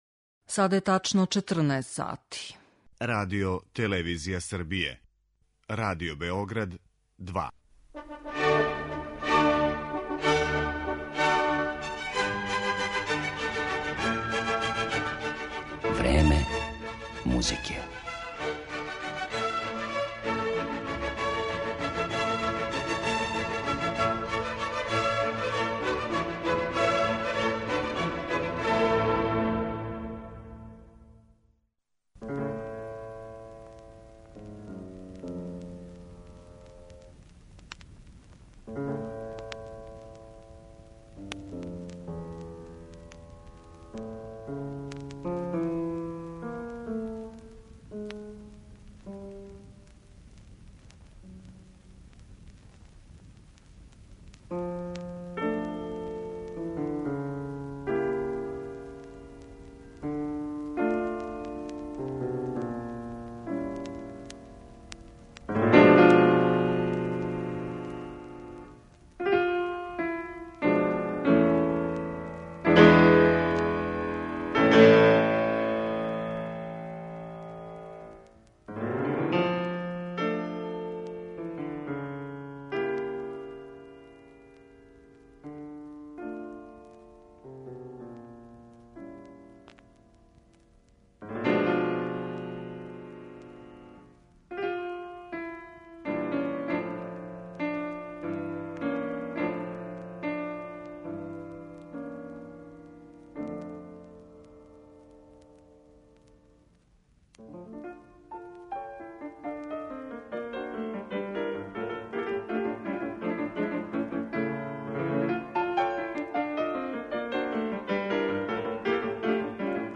Клавирски дуо